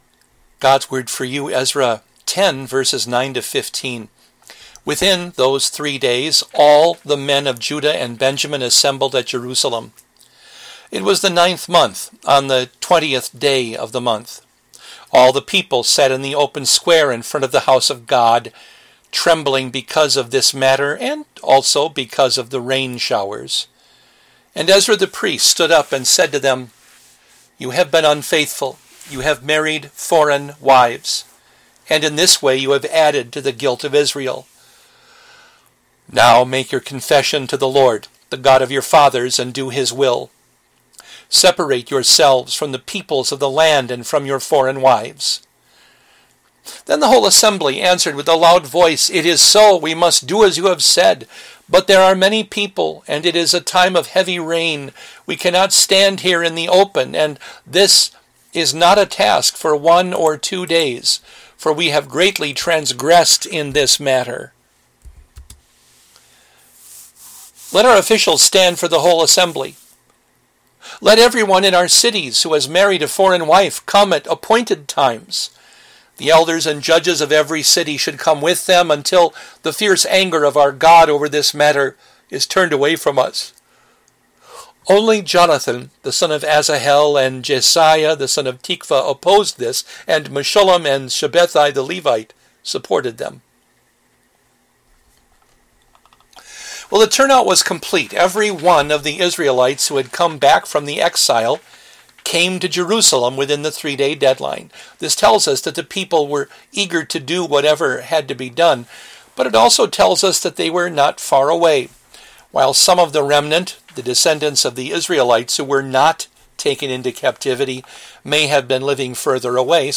A daily devotion